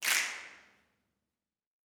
SNAPS 11.wav